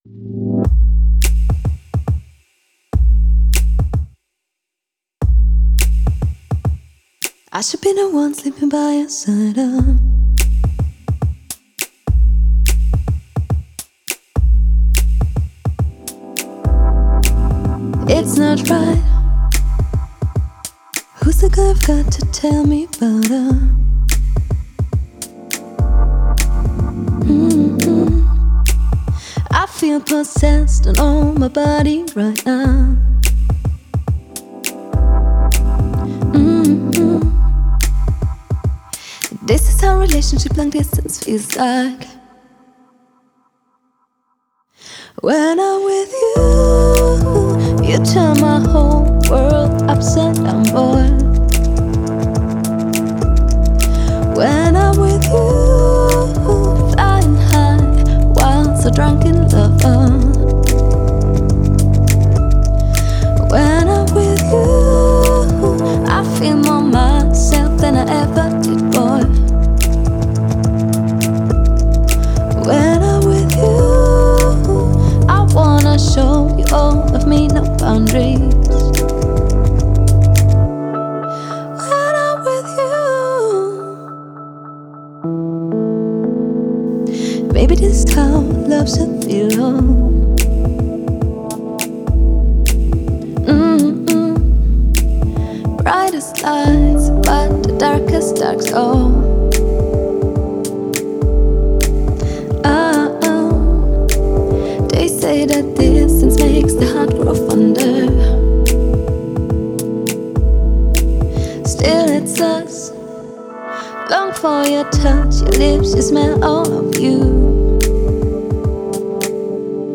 ehrlich, verträumt und mitreißend.